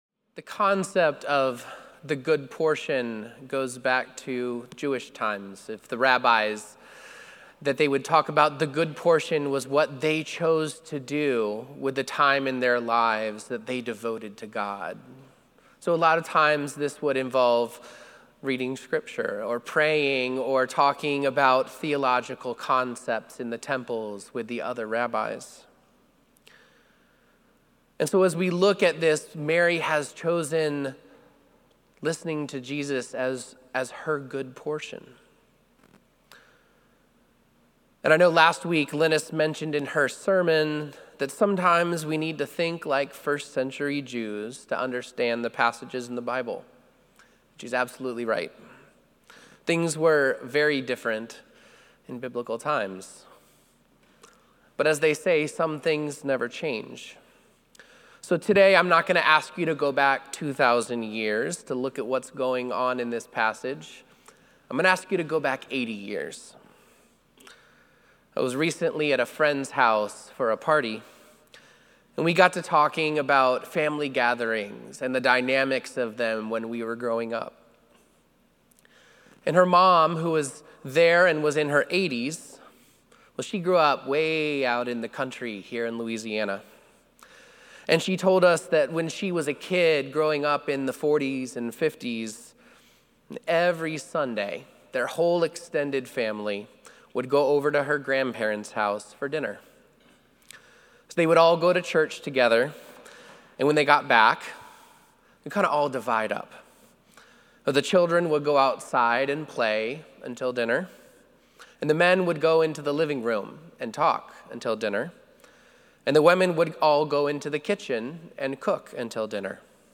Sermon+7-20-25.mp3